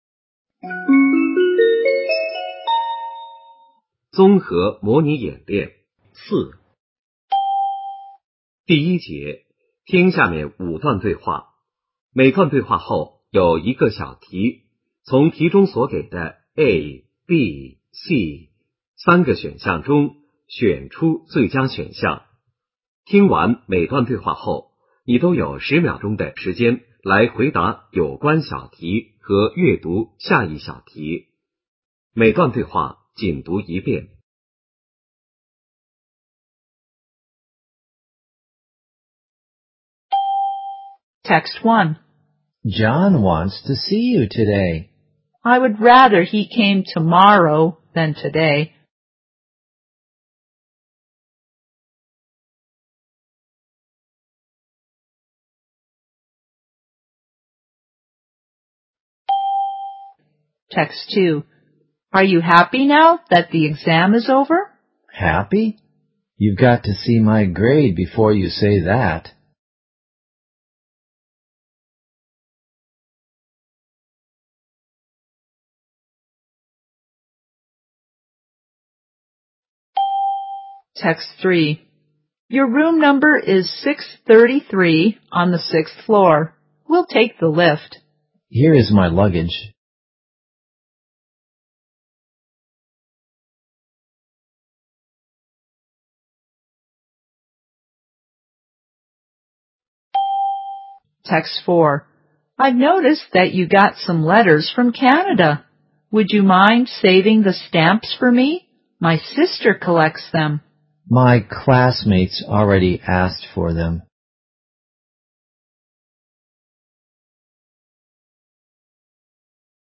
听力与训练